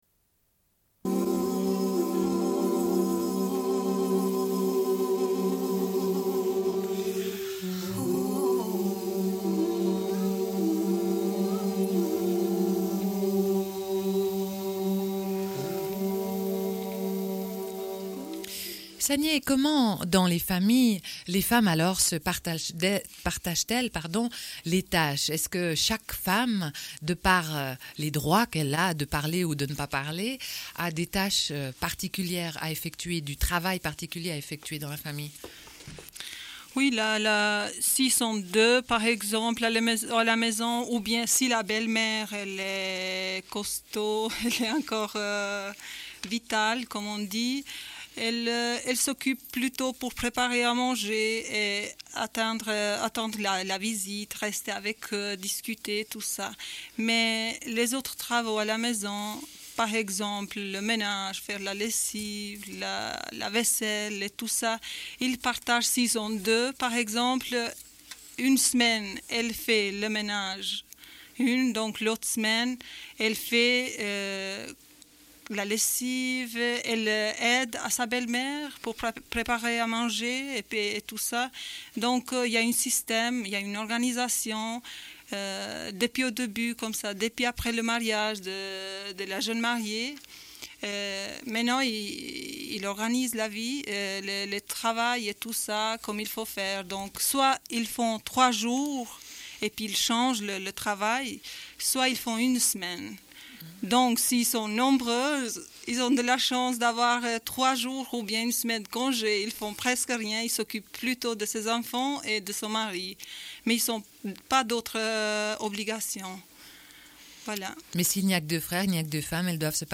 Une cassette audio, face B00:29:01